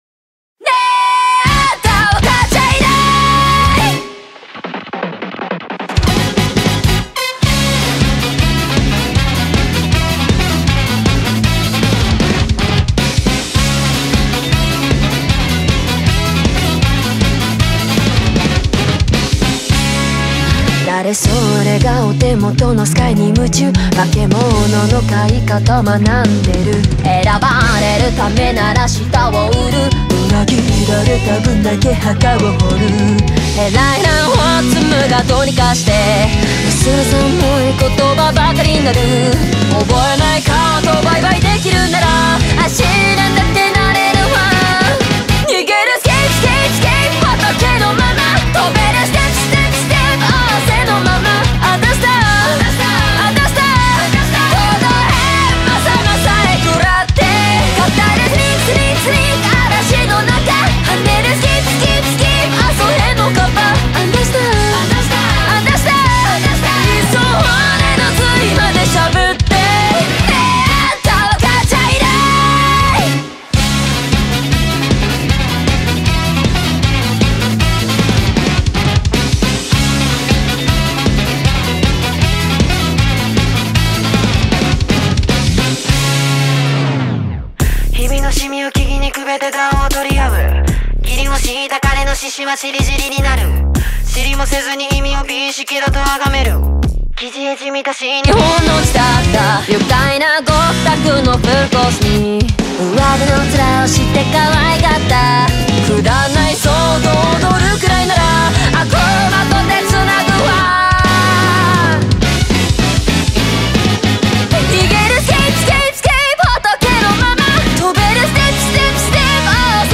BPM156
Audio QualityCut From Video